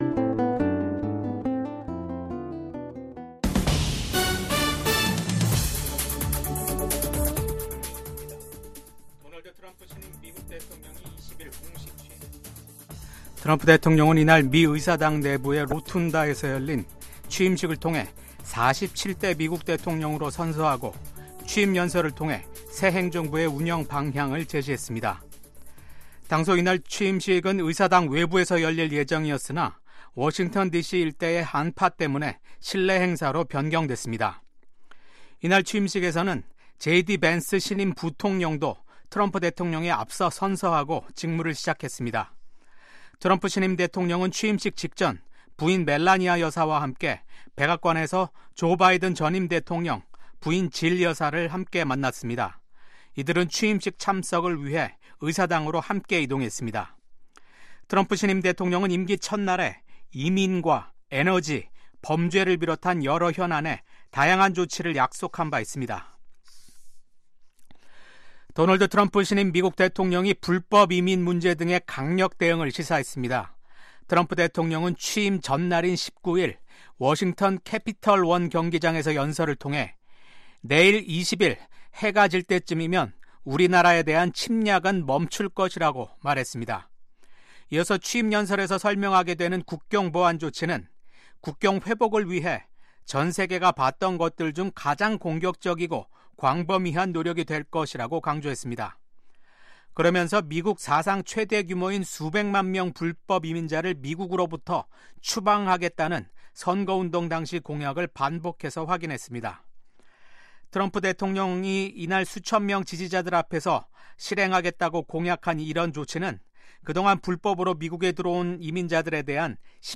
VOA 한국어 아침 뉴스 프로그램 '워싱턴 뉴스 광장'입니다. 미국의 제47대 도널드 트럼프 대통령의 취임식 날입니다. VOA한국어 방송의 뉴스투데이는 오늘, 취임식 특집 방송으로 진행합니다.